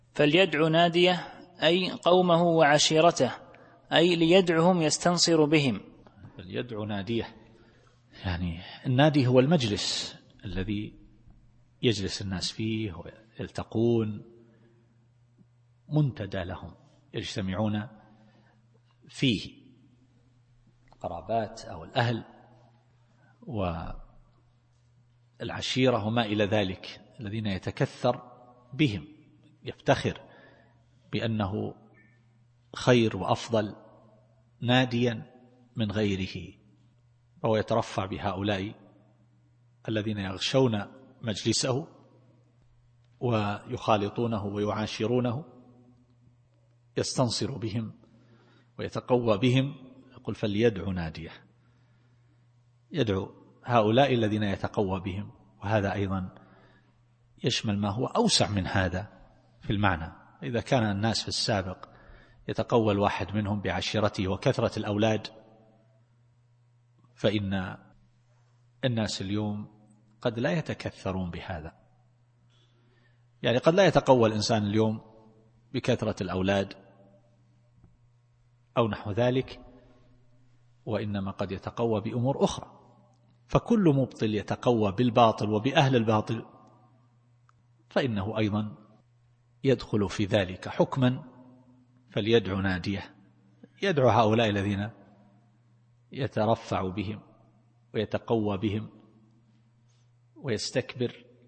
التفسير الصوتي [العلق / 17]